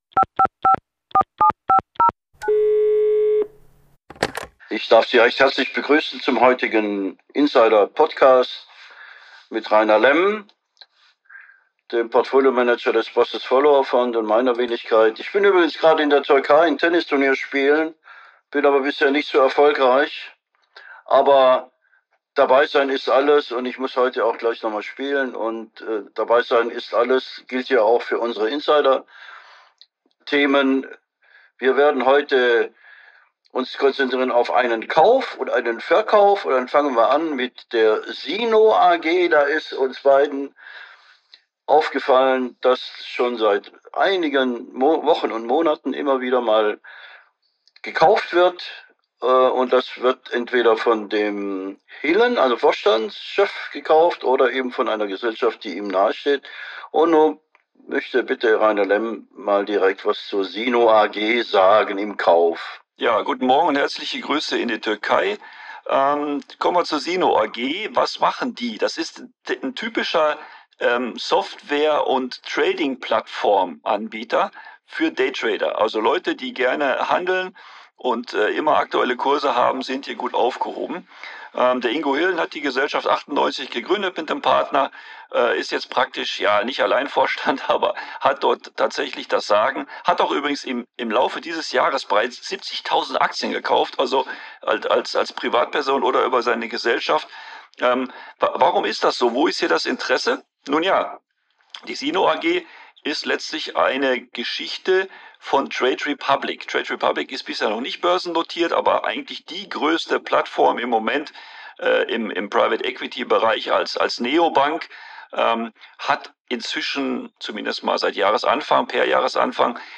live aus der Türkei